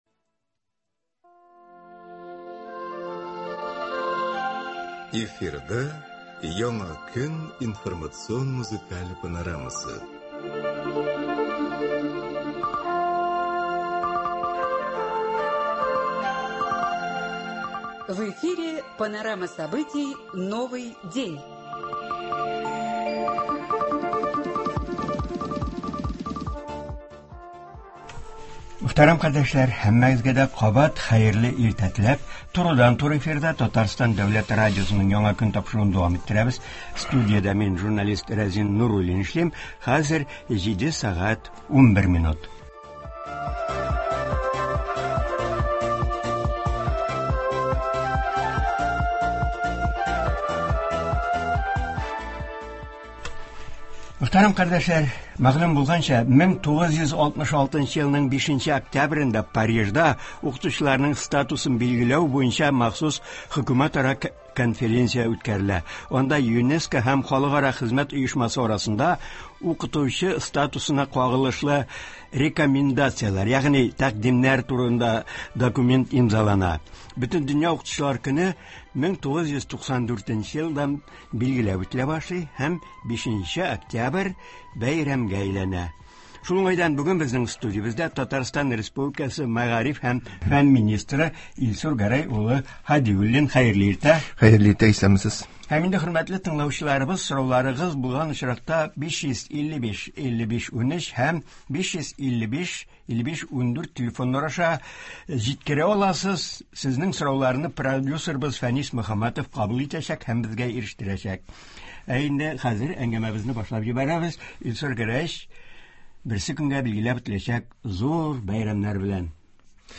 Туры эфир (03.10.22)
Болар хакында турыдан-туры эфирда Татарстан республикасы мәгариф һәм фән министры Илсур Гәрәй улы Һадиуллин сөйләячәк, алдынгы коллективларны, тәҗрибәле укытучыларны телгә алачак, шулай ук Удмуртиядә булган фаҗигале вакыйгалар башкача кабатланмасын өчен күрелә торган чаралар турында сөйләячәк, тыңлаучылар сорауларына җавап бирәчәк.